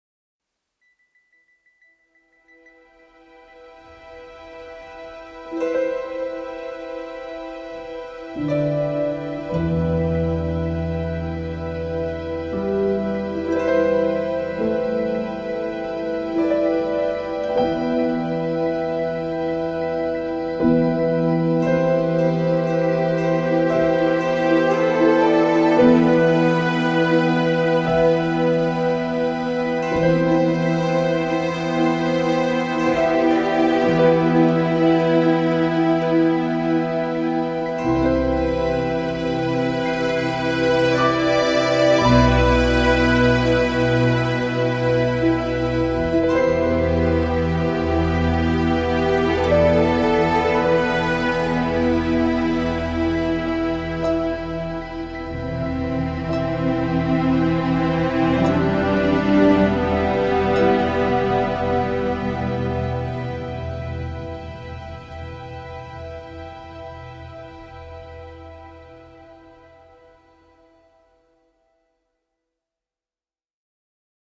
Le piano, les violons, les rythmes, tout sonne très juste.